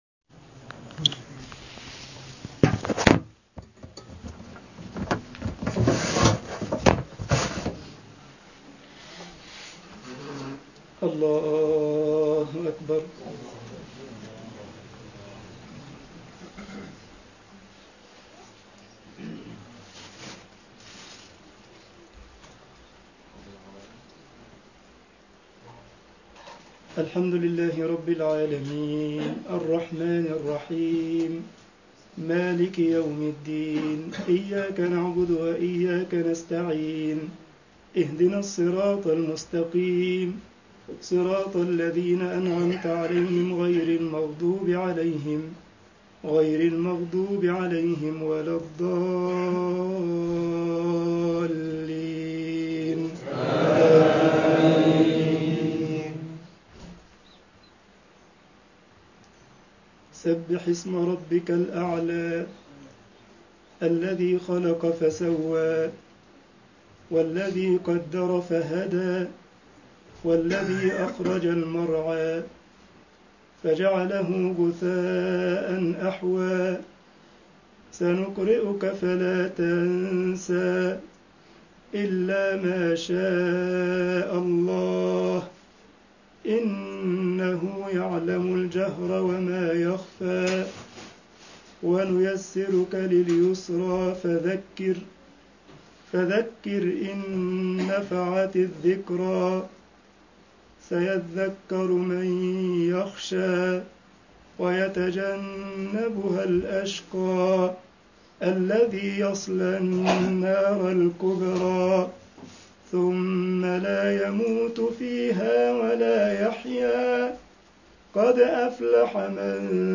دعاء قنوت ليلة 29 من رمضان 1437 هـ
تسجيلات نادرة دعاء قنوت ليلة 29 من رمضان 1437 هـ طباعة البريد الإلكتروني التفاصيل كتب بواسطة: admin المجموعة: تسجيلات نادرة Download التفاصيل نشر بتاريخ: الخميس، 07 تموز/يوليو 2016 12:07 الزيارات: 1942 التالي